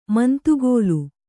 ♪ mantugōlu